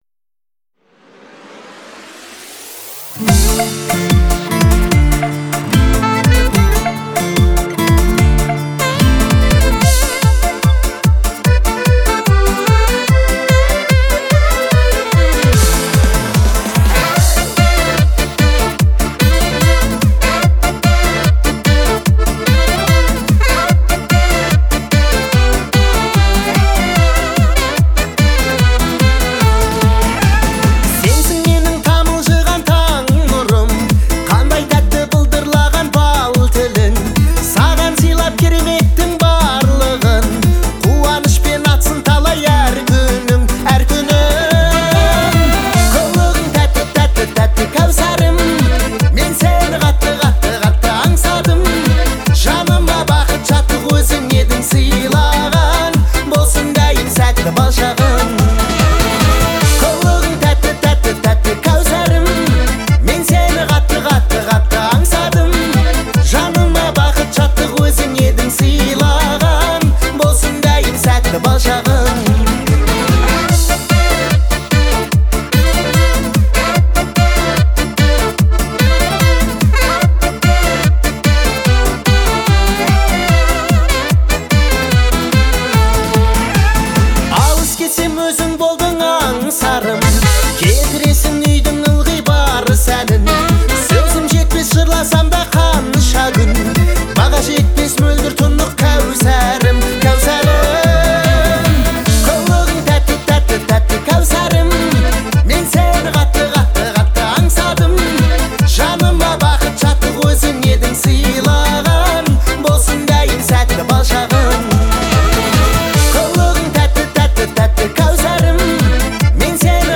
это трогательная баллада в жанре казахской поп-музыки.